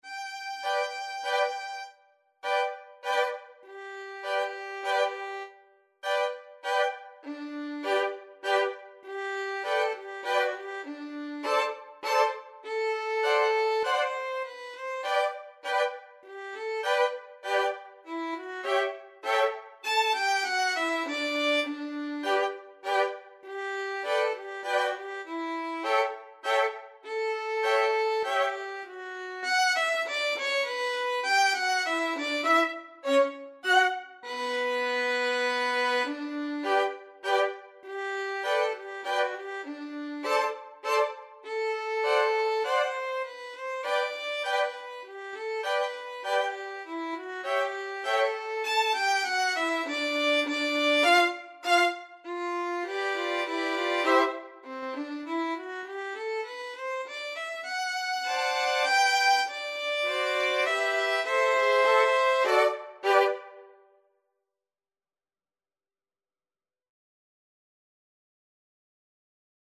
Free Sheet music for Violin Quartet
Violin 1Violin 2Violin 3Violin 4
3/4 (View more 3/4 Music)
G major (Sounding Pitch) (View more G major Music for Violin Quartet )
Slowly = c.100
Violin Quartet  (View more Easy Violin Quartet Music)